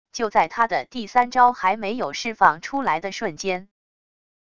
就在他的第三招还没有释放出来的瞬间wav音频生成系统WAV Audio Player